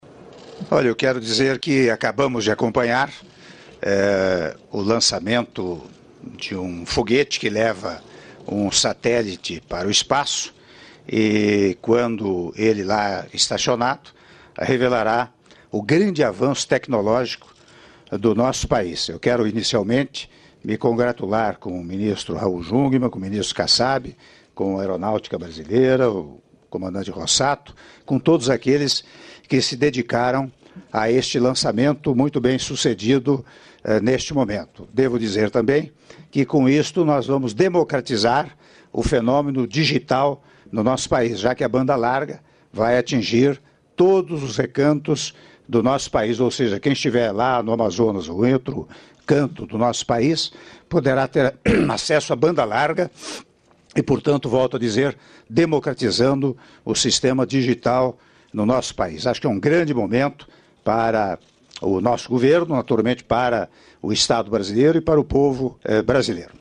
Áudio da declaração à imprensa do Presidente da República, Michel Temer, após Transmissão do Lançamento do Satélite Geoestacionário de Defesa e Comunicações Estratégicas – SGDC - Brasília/DF (01min06s)